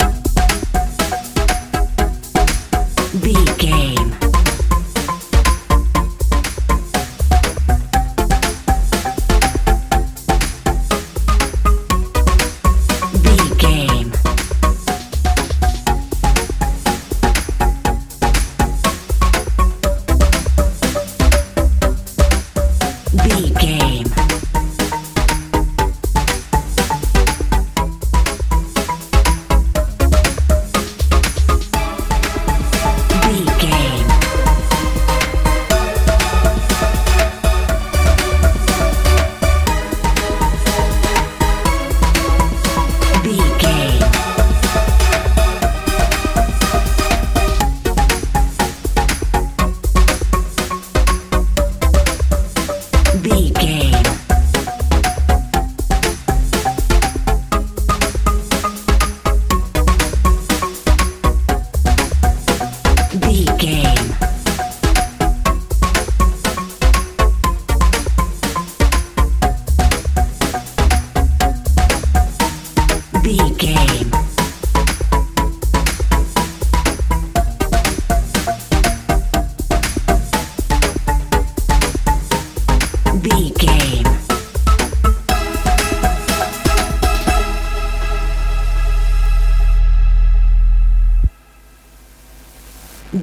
house feel
Ionian/Major
A♭
bouncy
lively
synthesiser
bass guitar
drums